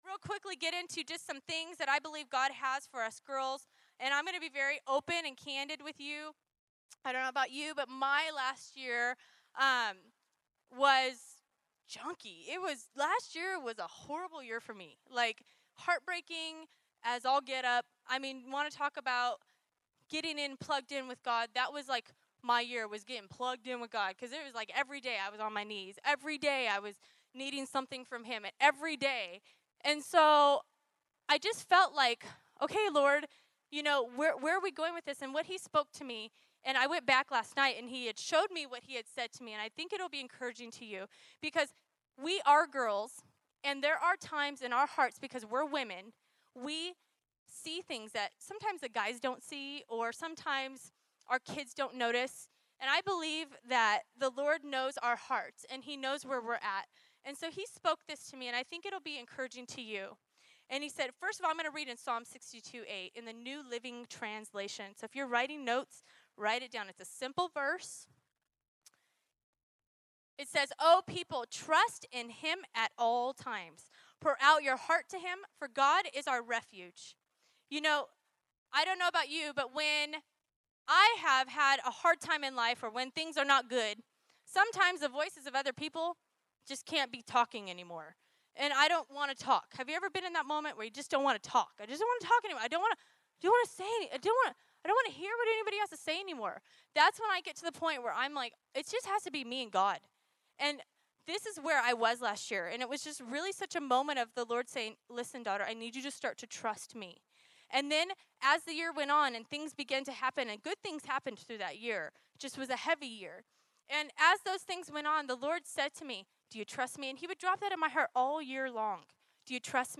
New Year's Panel